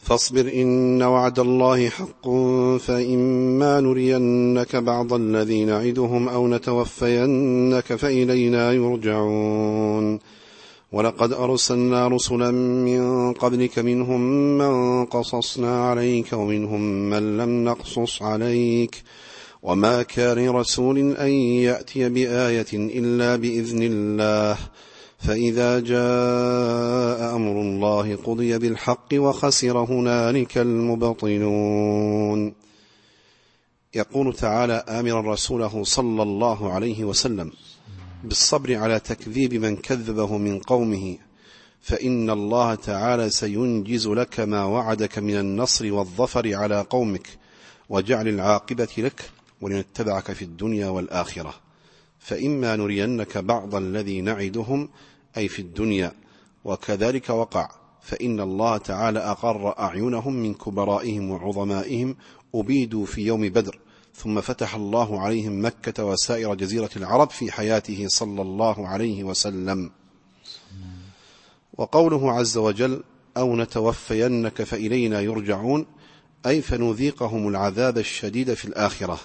التفسير الصوتي [غافر / 77]